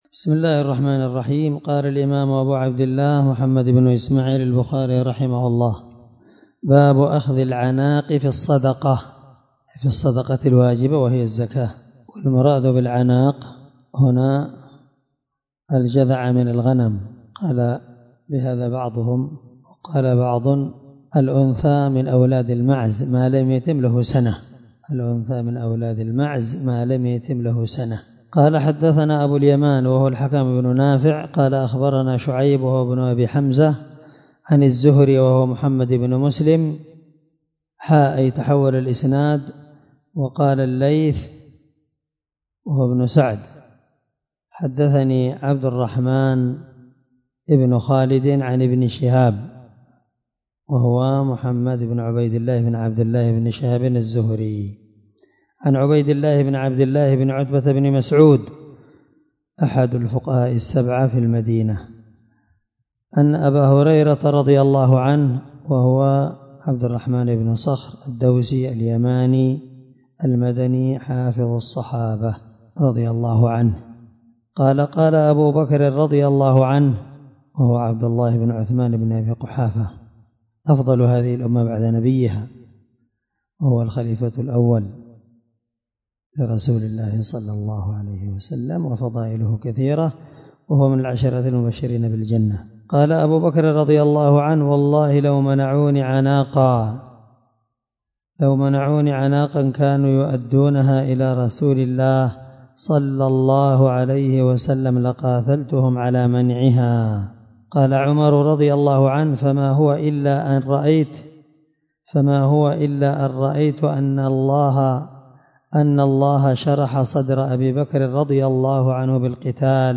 الدرس 37من شرح كتاب الزكاة حديث رقم(1456-1457 )من صحيح البخاري